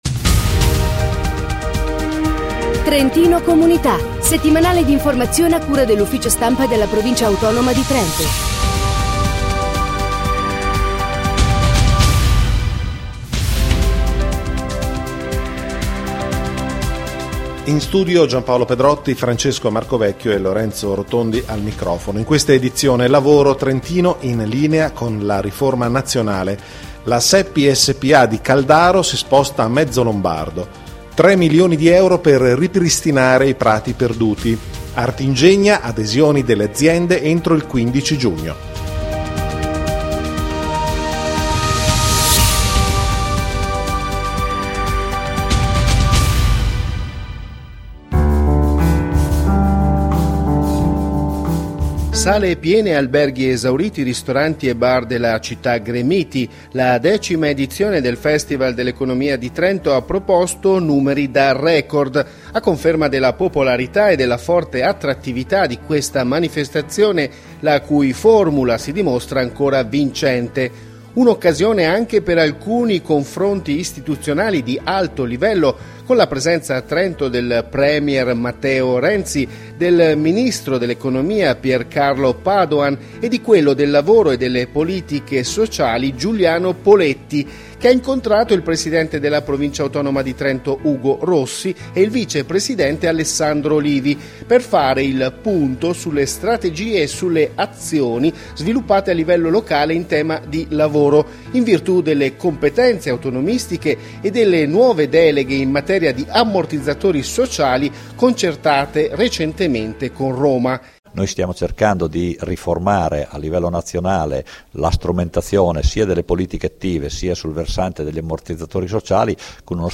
Notiziario Ambiente Economia, imprese e attività produttive Lavoro e occupazione